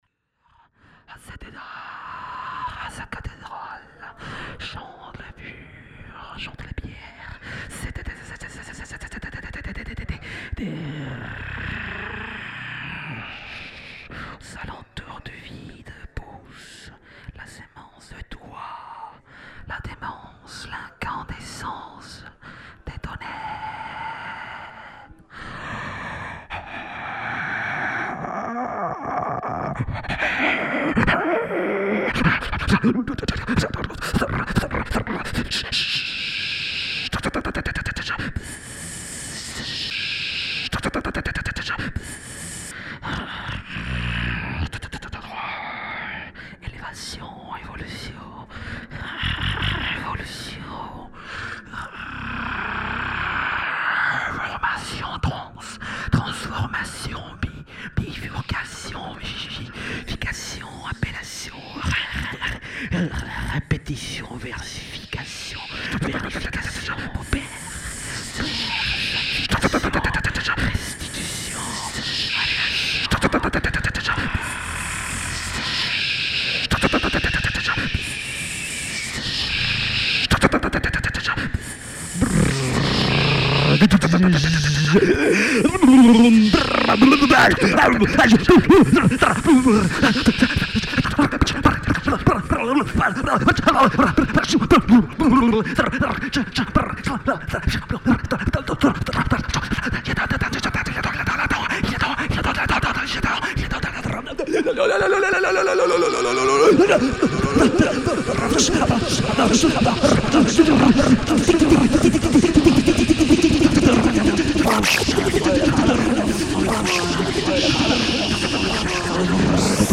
live galerie nuit d' encre